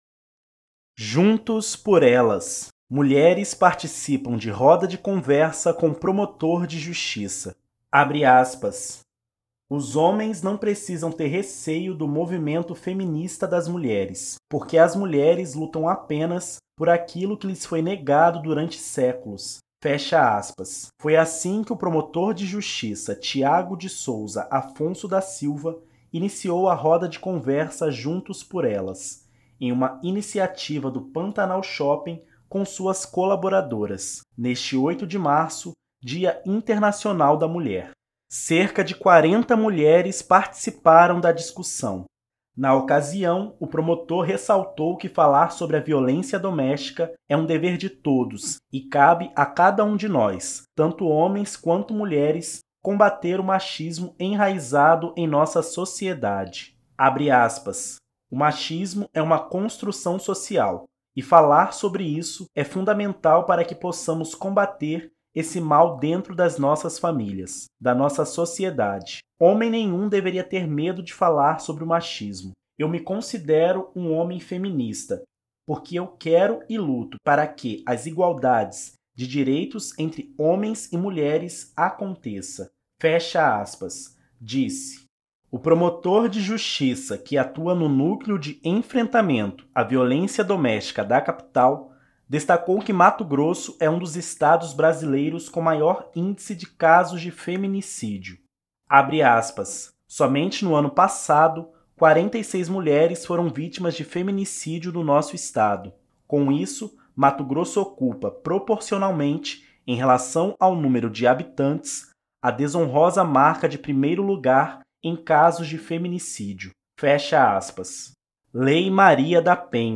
Mulheres participam de roda de conversa com promotor de Justiça
Mulheres participam de roda de conversa com promotor de Justiça.mp3